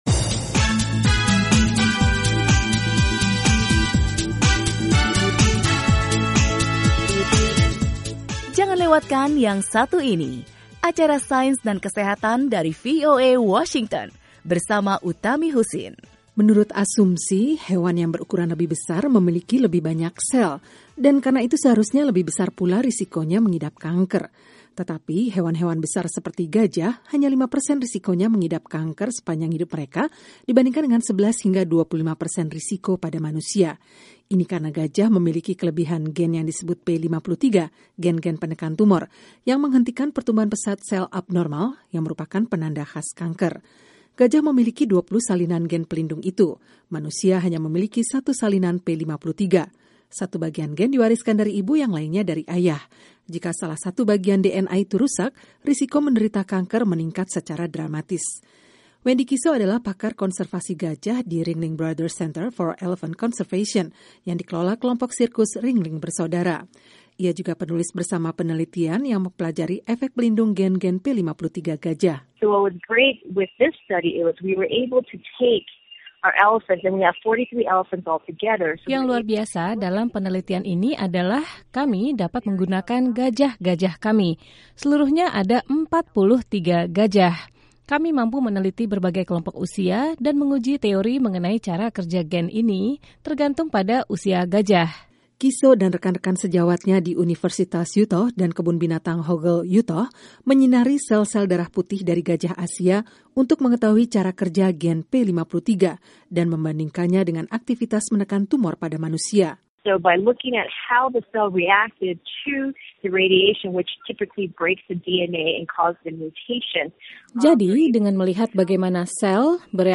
Para ilmuwan telah lama meneliti gajah. Apa yang ditemukan mengenai hewan itu kelak mungkin dapat menyelamatkan nyawa anak-anak yang mengidap penyakit langka yang menyebabkan kanker. Laporan